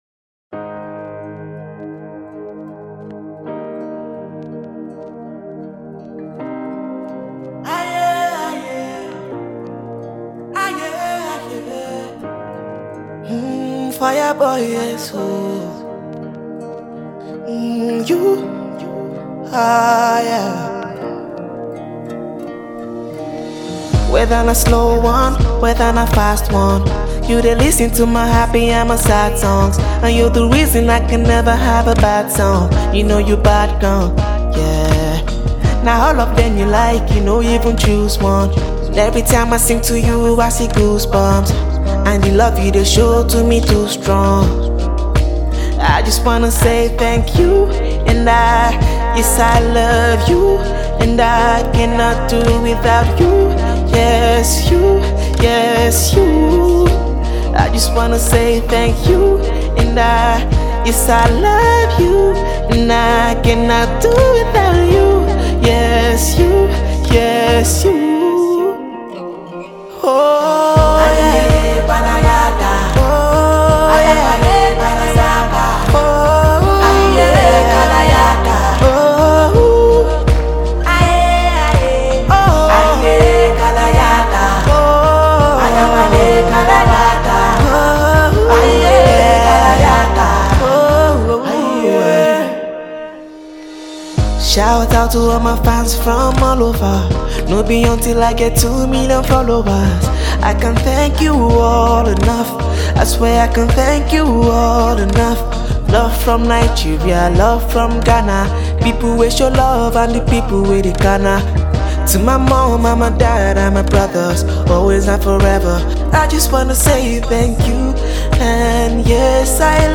nerve-calming